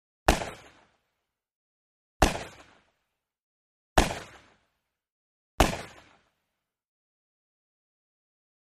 .357 Magnum Revolver: Single Shot ( 4x ); Four Sharp, Loud Single Shots With Short Echo. Close Up Perspective. Gunshots.